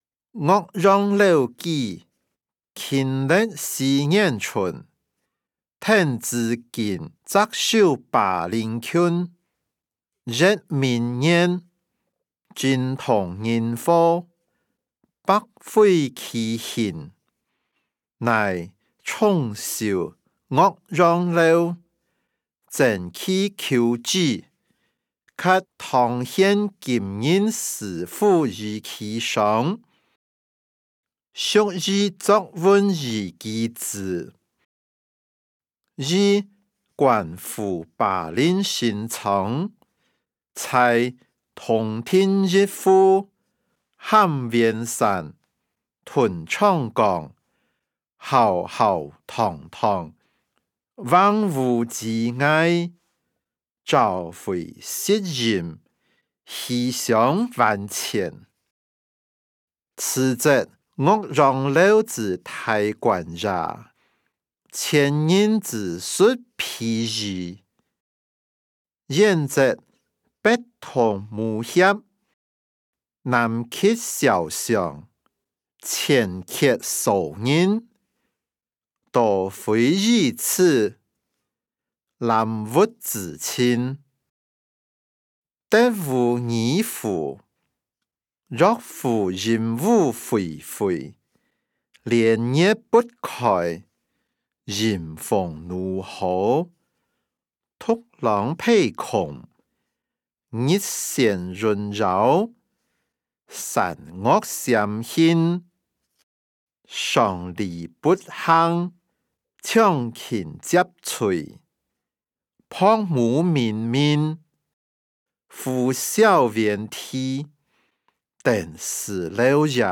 歷代散文-岳陽樓記音檔(饒平腔)